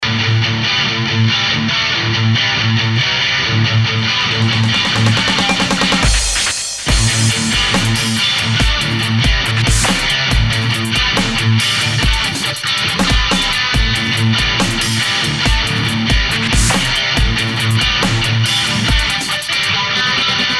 Немного ткань всё-таки "перепрозрачнена".
Заменил стаккато на тремоло.
Это действительно хорошее эмоциональное сопровождение, мне понравилось еще и то, что там из смутного потока естественно определилась довольно ясная и симпатичная мелодическая тема.
Я заменил в первых скрипках на тремоло.
Просто дальше они идут в унисон первым скрипкам и подчеркивают отрывистость.